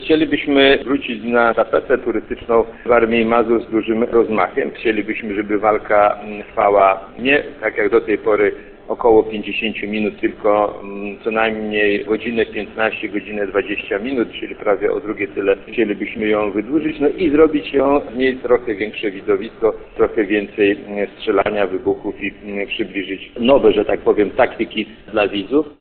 W rozmowie z Radiem 5 burmistrz Włodkowski poinformował, że z kalendarza wypadły też takie imprezy jak Dni Orzysza czy Bieg Tygrysa.